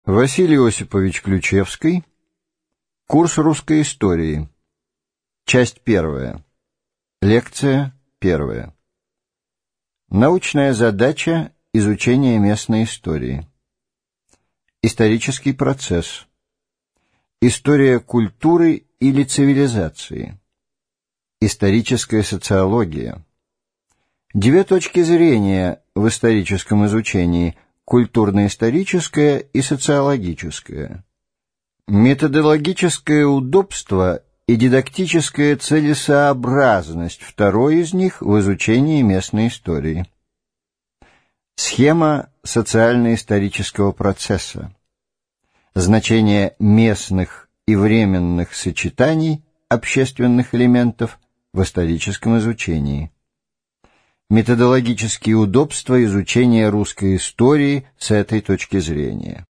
Аудиокнига Курс русской истории в 5-ти частях | Библиотека аудиокниг